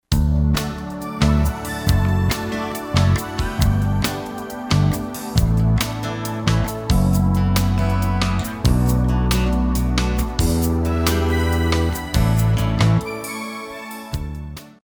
Contemporary 95b